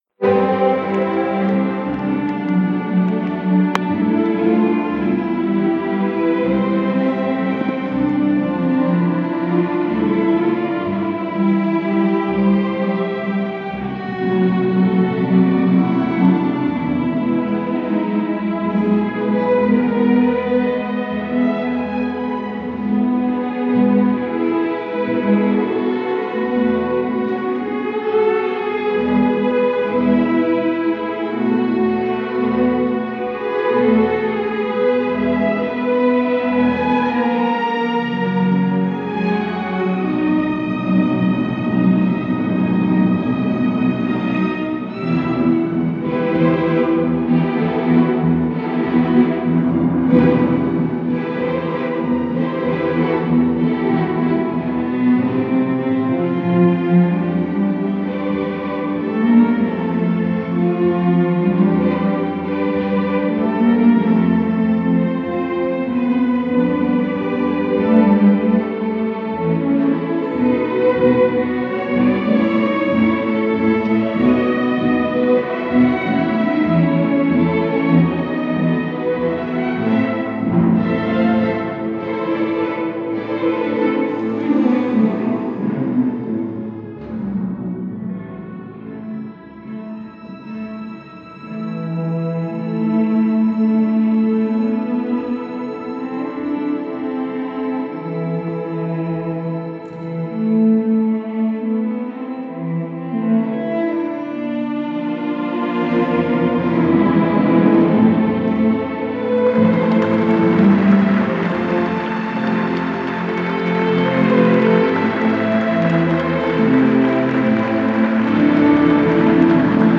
C'est un groupe instrumental de jeunes dont l'âge varie entre 12 et 20 ans.